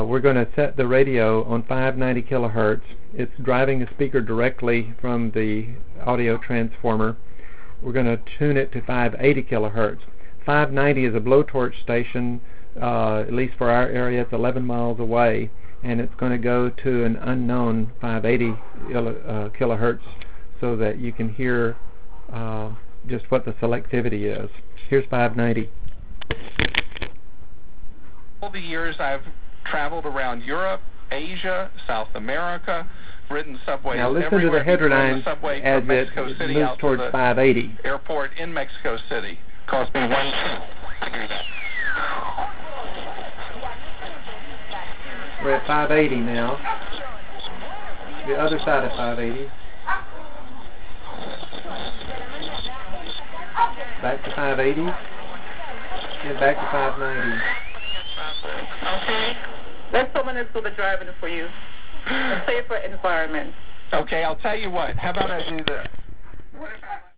It was recorded  in front of an 8 ohm speaker driven by this radio.